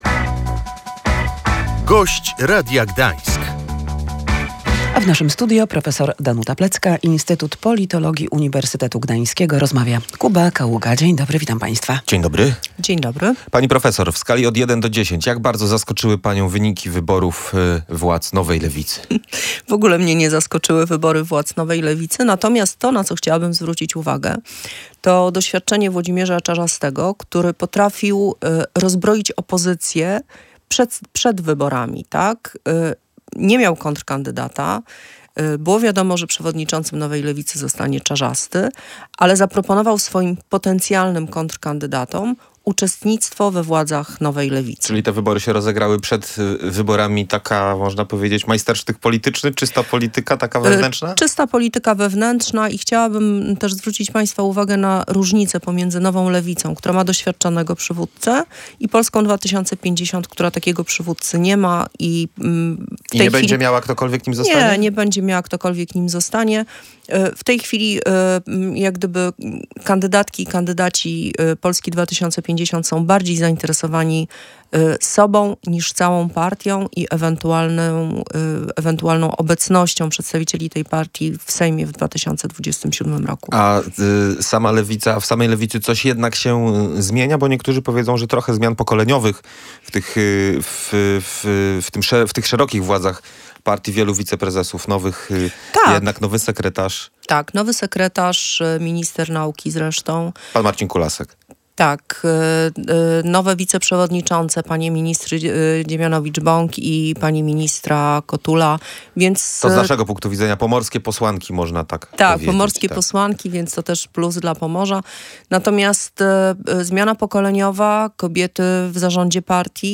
w studiu Radia Gdańsk